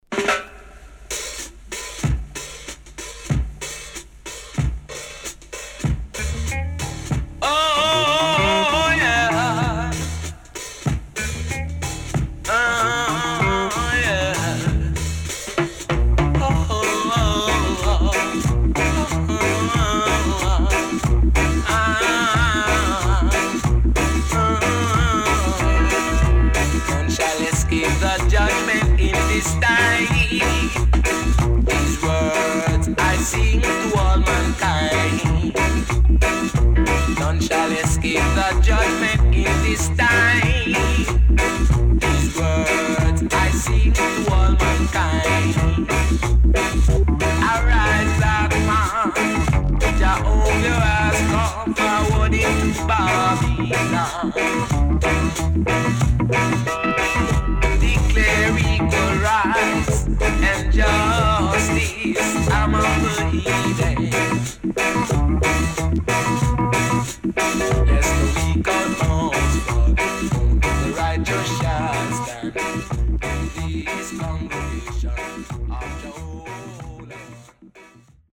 HOME > REISSUE [REGGAE / ROOTS]
♪DUB♪